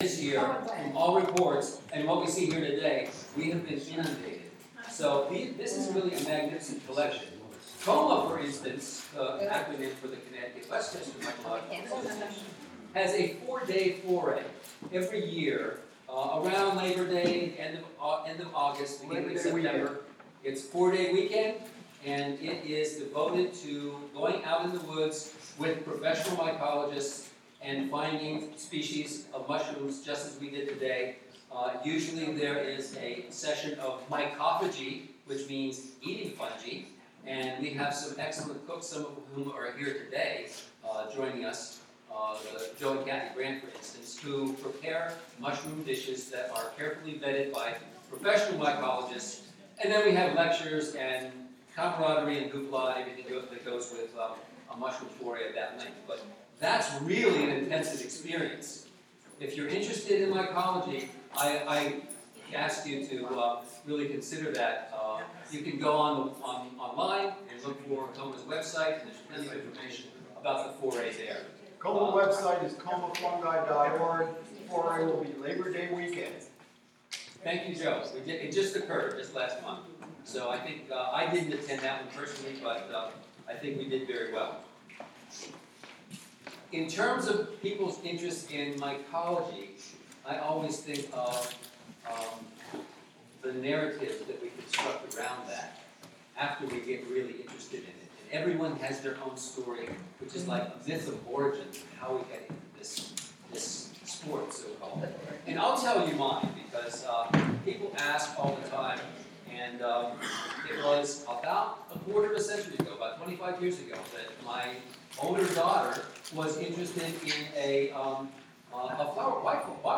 Recorded and streamed by Wave Farm.
John Cage was an enthusiastic mycologist who expressed his interest in fungi in his music, his text compositions, and his visual art works, as well as in his lifelong mushroom collecting and identification activities. Organized by the John Cage Trust, John Cage Mycology Day, was a one-day event, featuring a mushroom walk and afternoon presentations, that celebrate Cage's passion for mushrooms and his multifarious contributions to mycology.